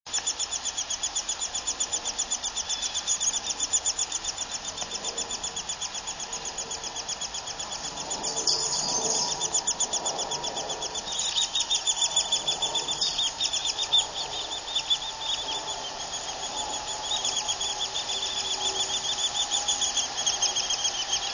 Nestlings - Baby Birds
Cheesequake State Park, Green Trail, 6/12/04, baby Downy Woodpecker nestlings/chicks crying for food, mother arriving and being fed (84kb).  Nest was about 30' high in a 6" wide dead tree trunk
woodpecker_downy_chicks_816.wav